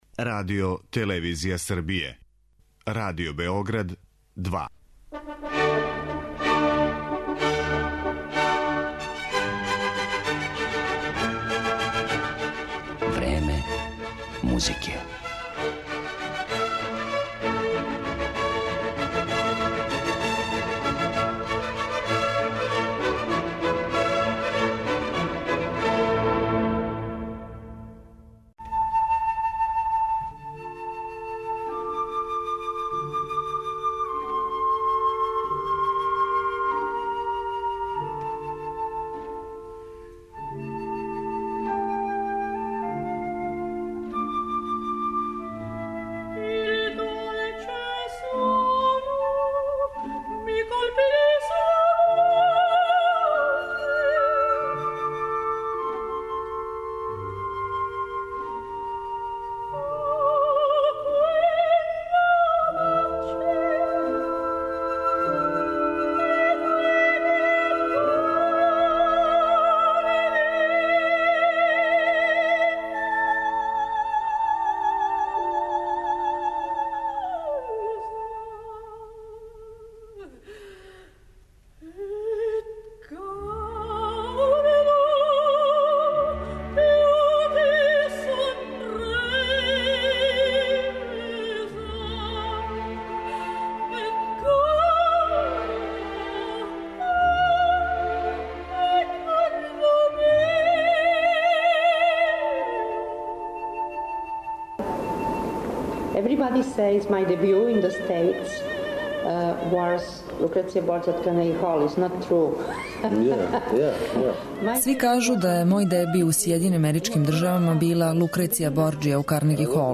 Биће емитован и интервју остварен са уметницом приликом њених гостовања код нас.